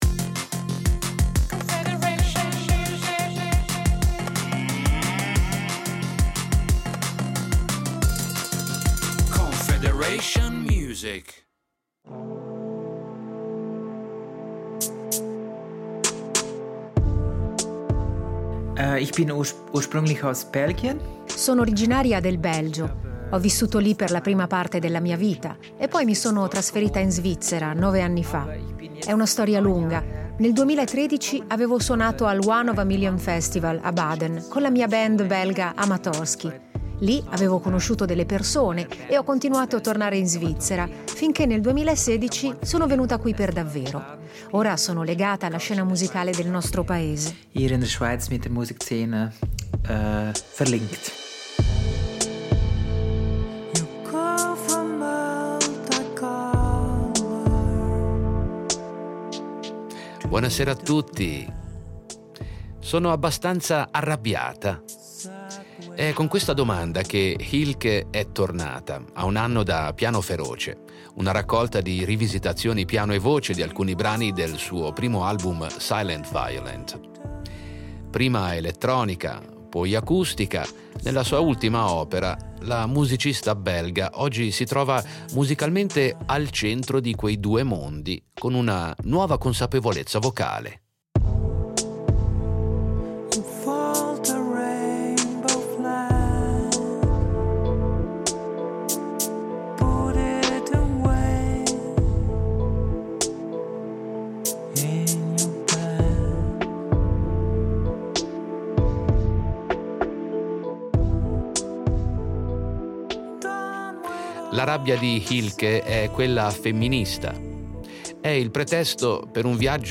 Musica pop
Il veicolo di questa nuova consapevolezza è un blend musicale malinconico fatto di paesaggi acustici, beats elettronici, campionamenti distopici e le carezze di un pianoforte.